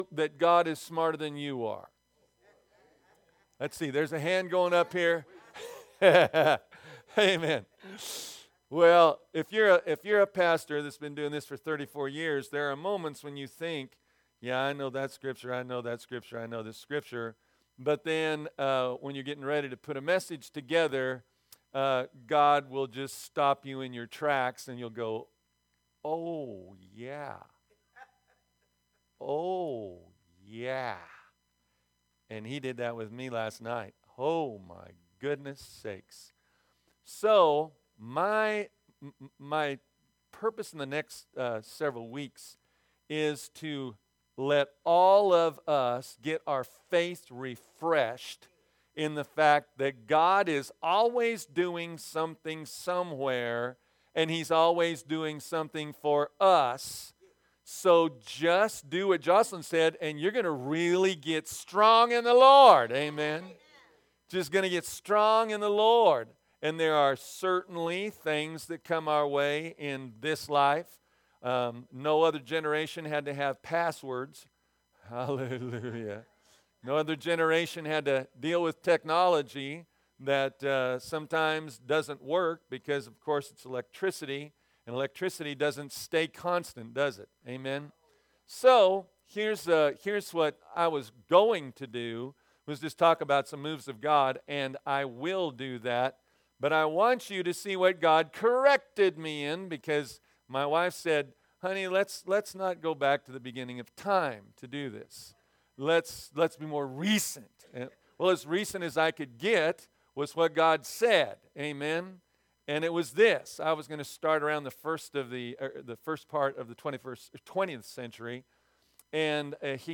Sermons | Victory Christian Fellowship